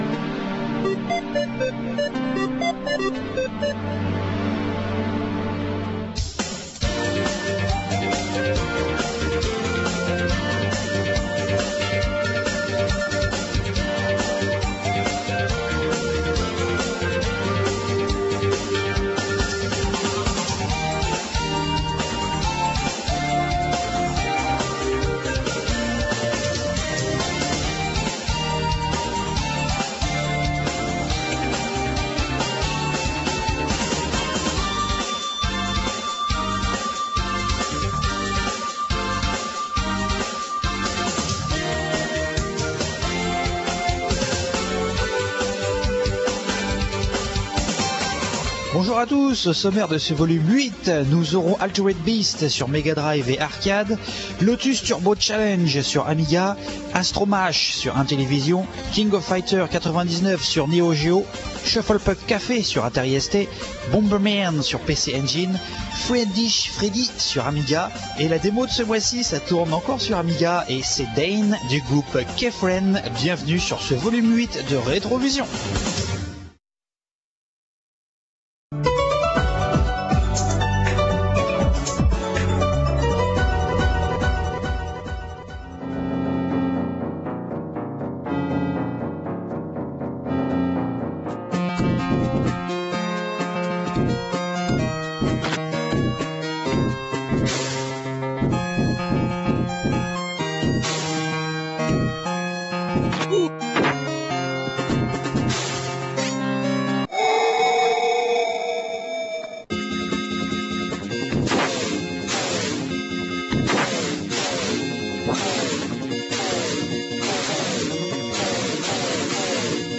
La qualité de la vidéo en streaming Real Multimedia est volontairement dégradée afin qu'elle soit rapidement téléchargée et affichée.